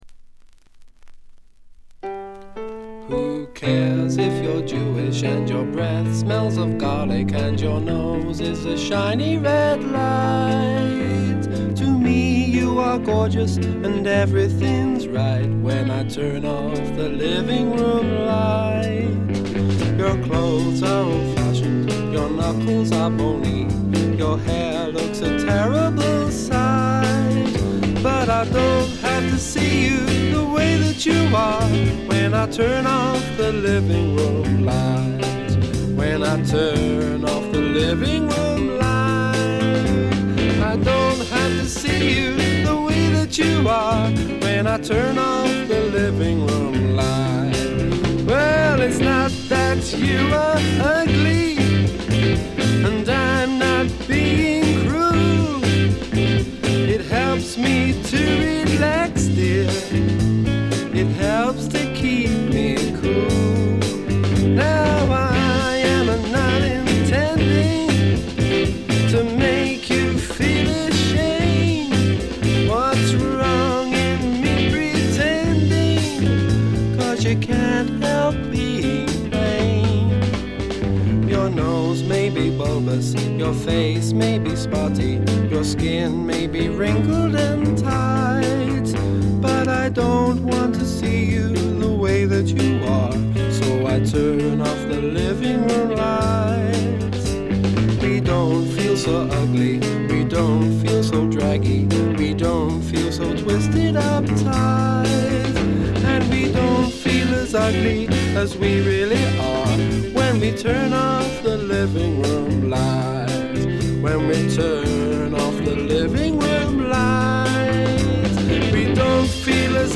チリプチがちらほら。
試聴曲は現品からの取り込み音源です。